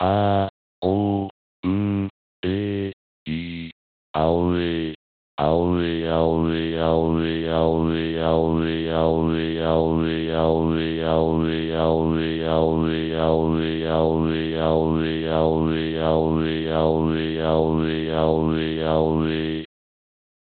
アオウエイ　　（音声読み上げソフト）
男声-2）
hz-aouei-mal-2.mp3